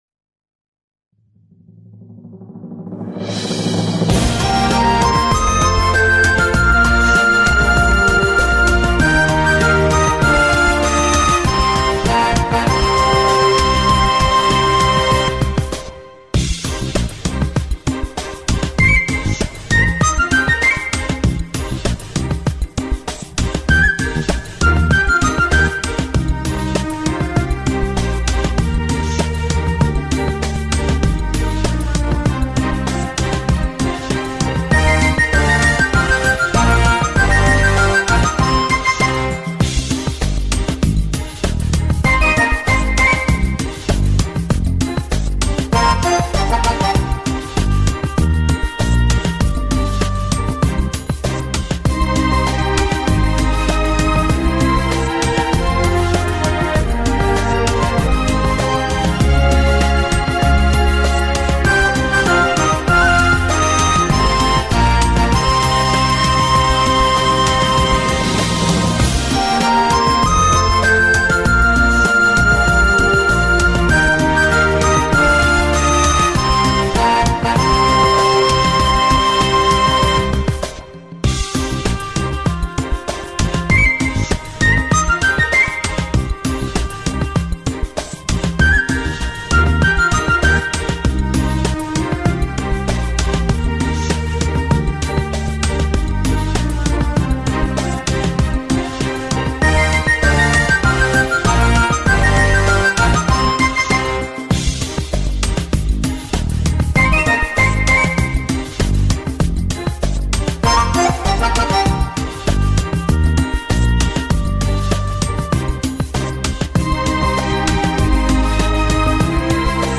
当前播放 和谐社会歌也多（含山民歌）-001
民歌